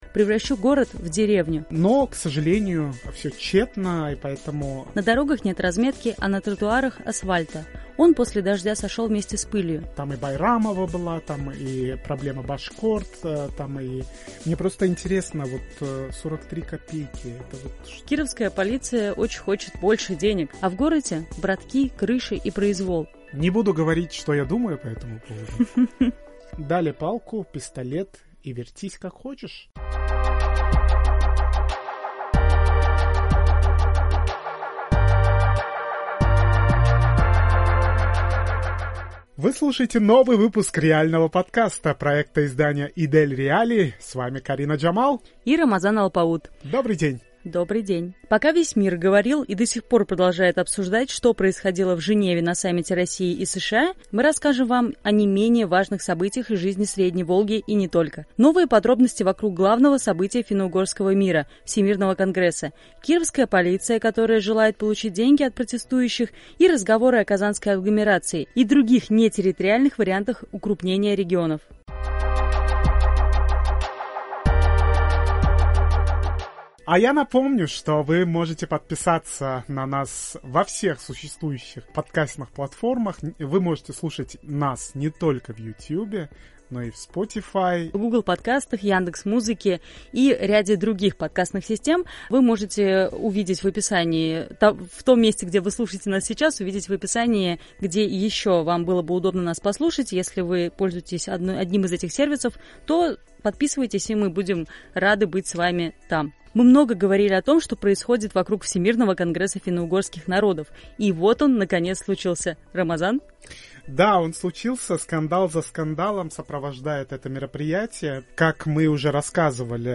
Обсуждают журналисты издания